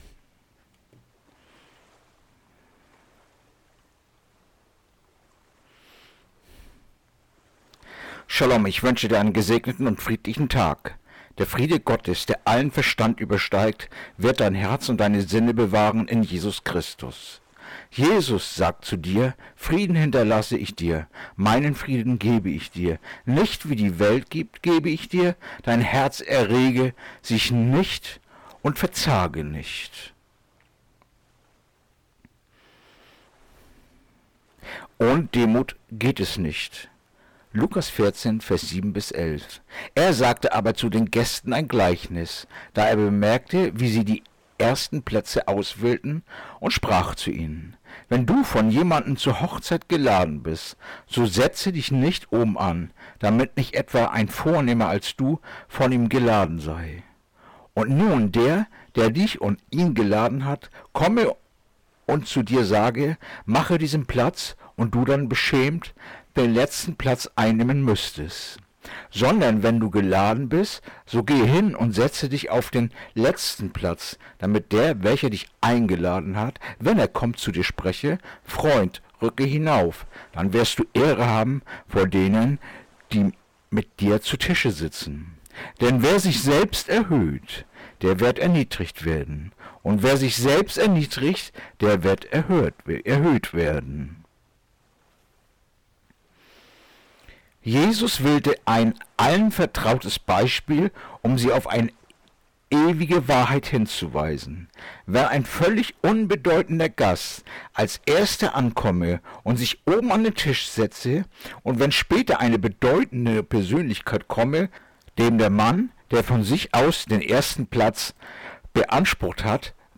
Andacht-vom-08.-Juli-Lukas-14-7-11-2